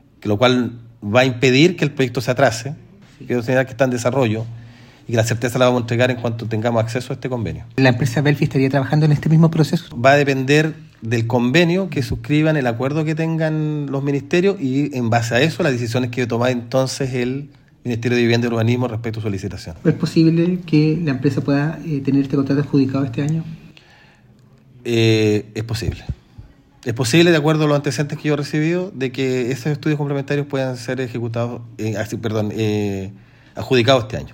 Ante ello, el delegado Alvial señaló que existen posibilidades de que se adjudique el proyecto, eso sí, dependiendo del convenio.